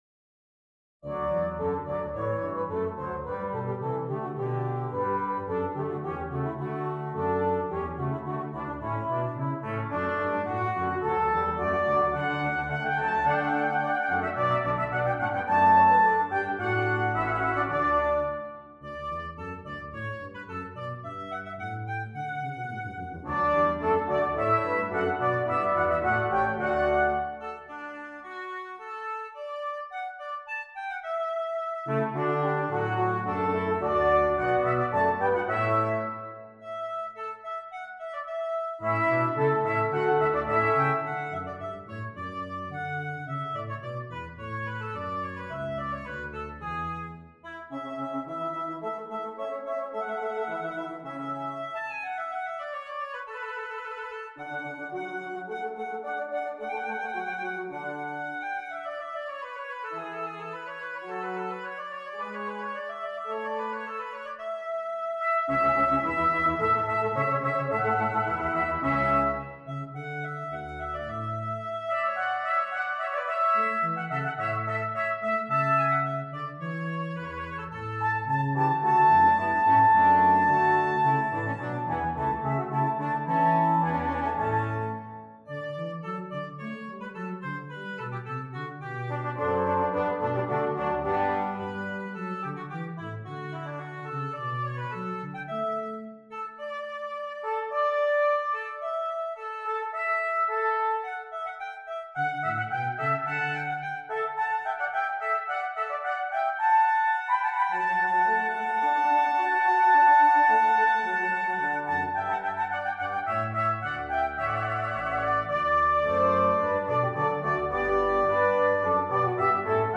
Brass Quintet and Soprano